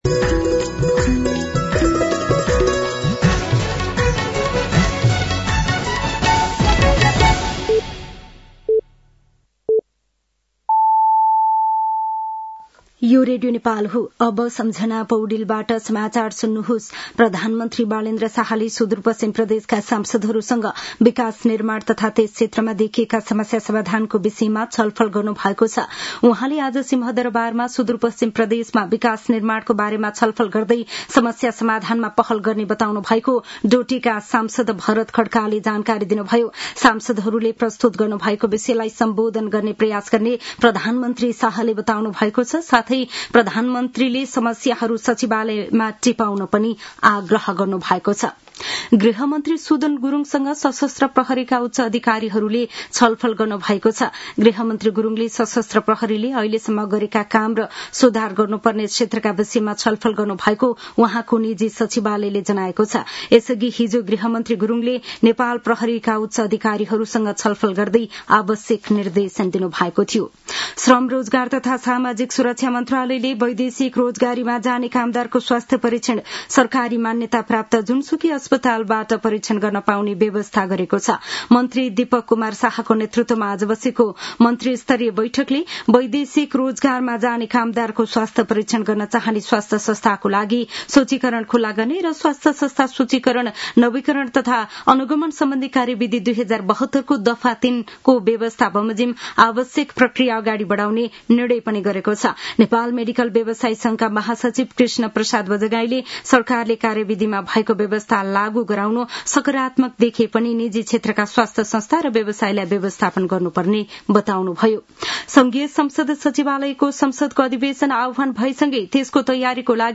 साँझ ५ बजेको नेपाली समाचार : १७ चैत , २०८२